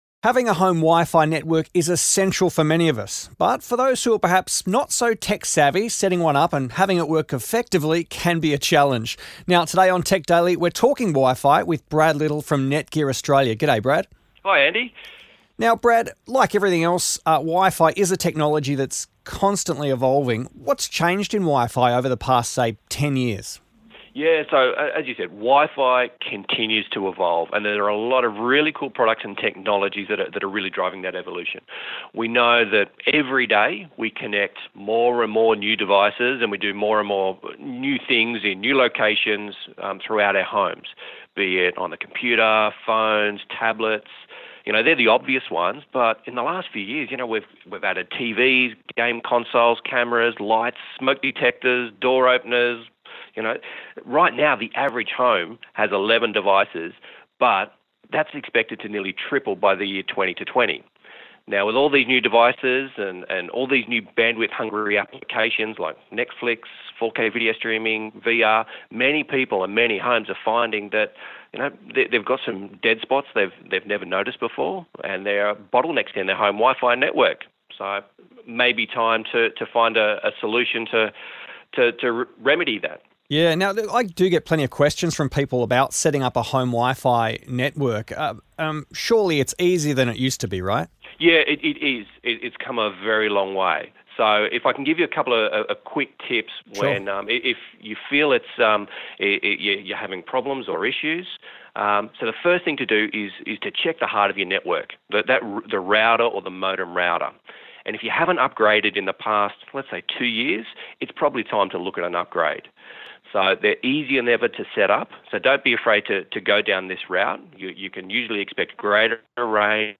on the phone